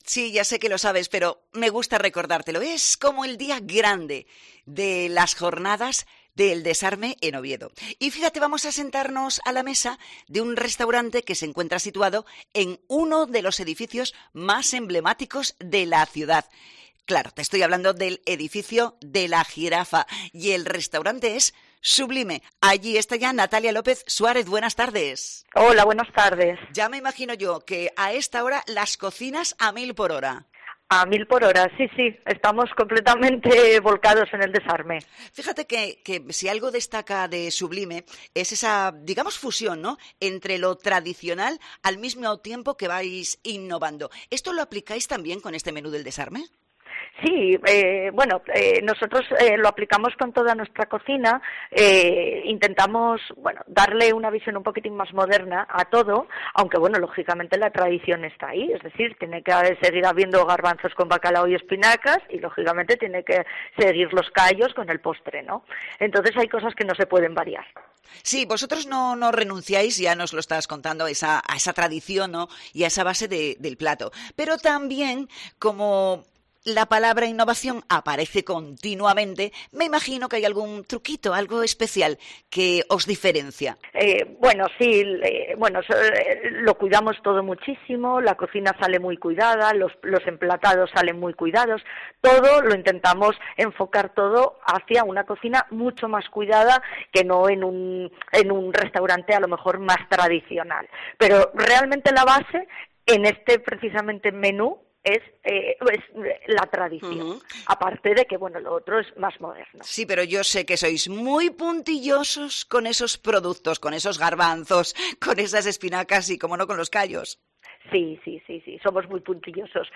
Jornadas del Desarme: entrevista